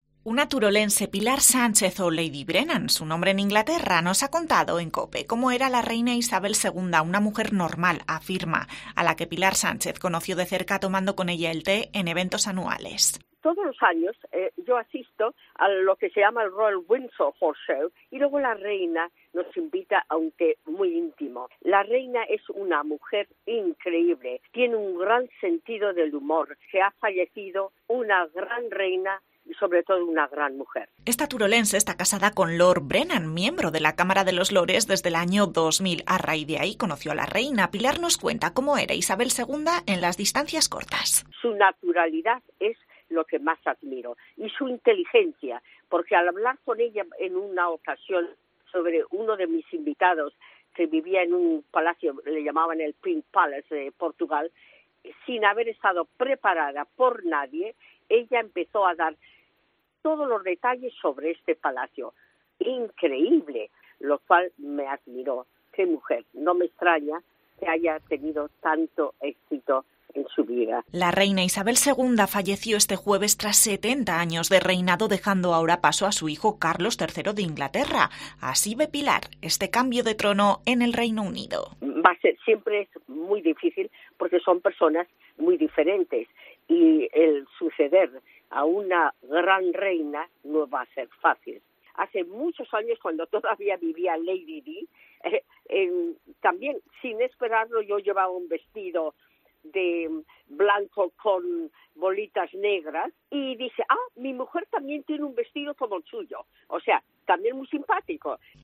Hablamos con una turolense